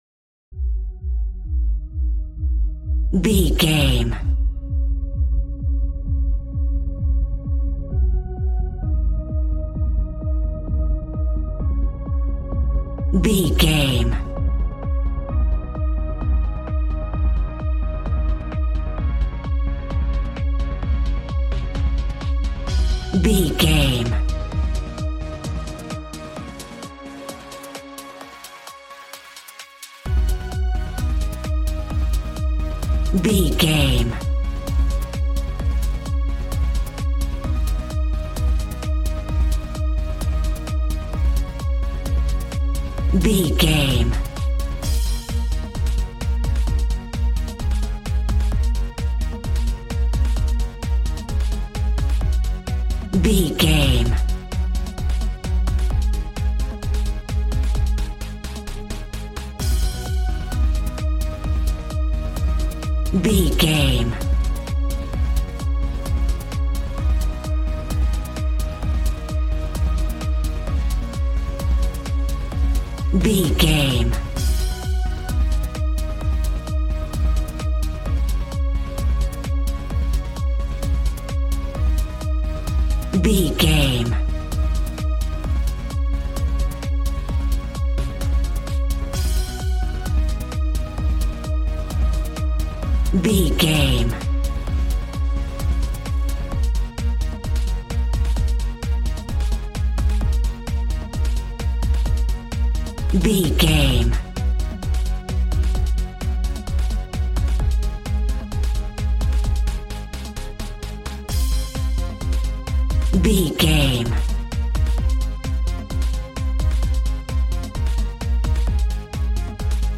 Ionian/Major
A♭
Fast
uplifting
lively
groovy
synthesiser
drums